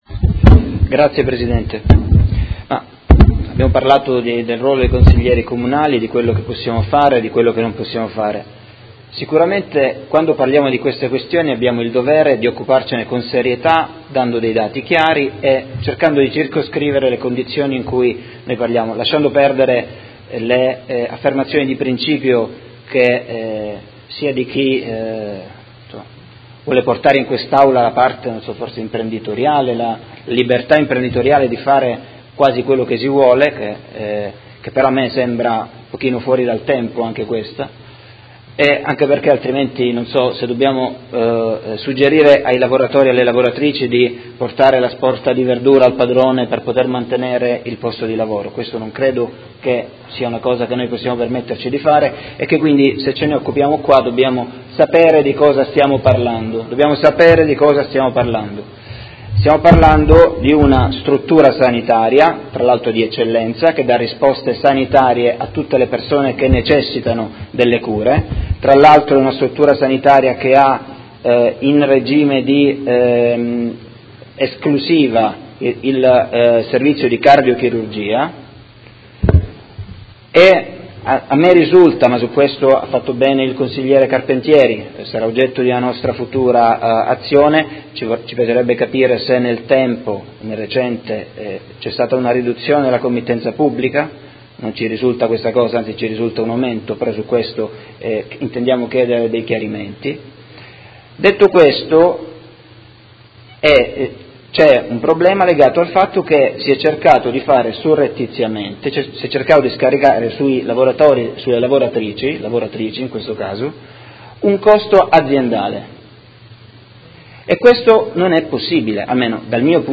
Seduta del 25/05/2017 Dibattito. Interrogazioni 61274 e 62840 sui lavoratori di Hesperia Hospital.